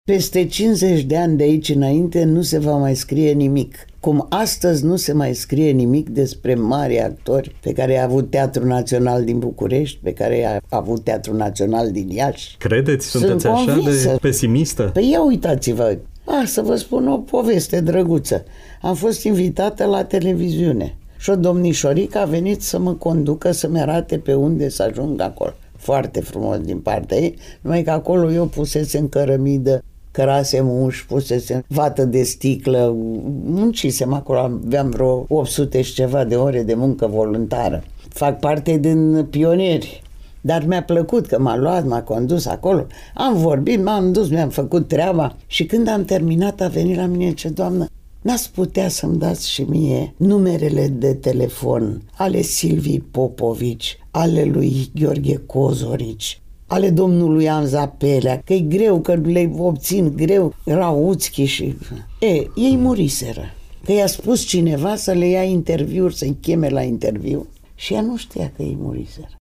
18-nov-rdj-12-Draga-Olteanu-Matei-la-Radio-Iasi.mp3